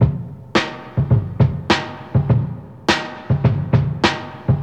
• 103 Bpm '90s Drum Loop A# Key.wav
Free drum loop - kick tuned to the A# note. Loudest frequency: 819Hz
103-bpm-90s-drum-loop-a-sharp-key-lTW.wav